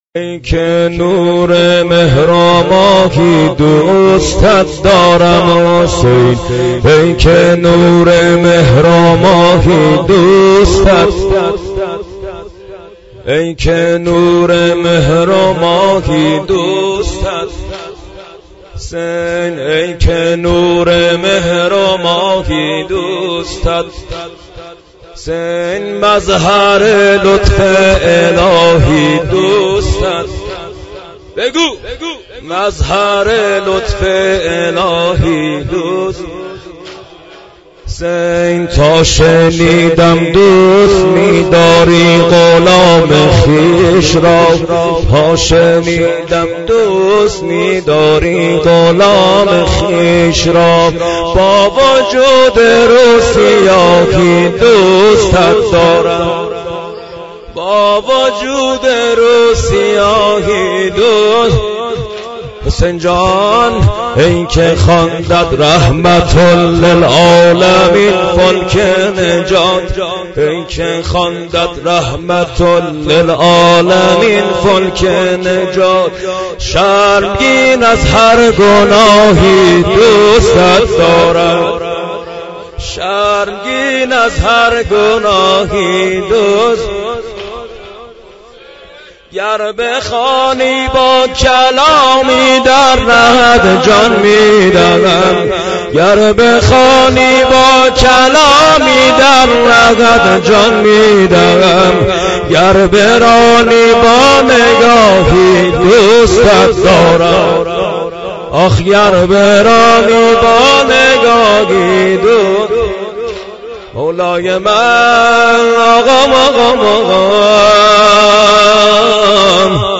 دانلود مداحی